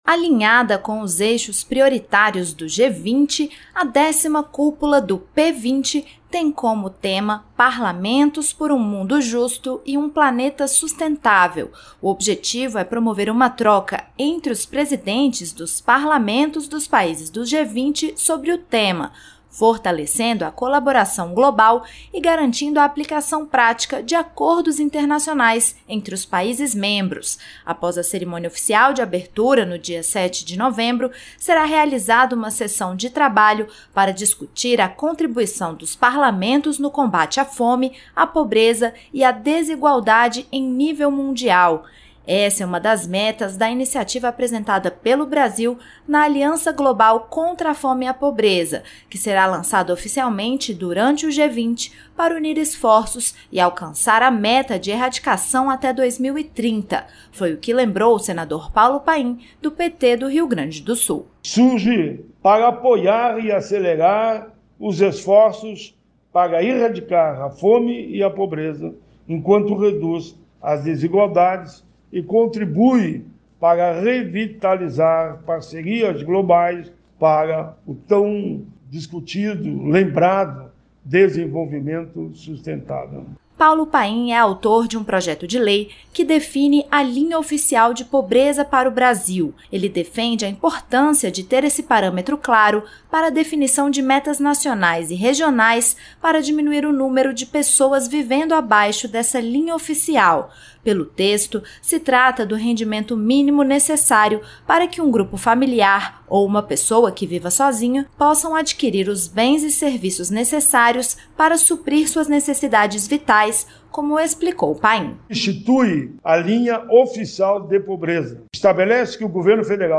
Senador Paulo Paim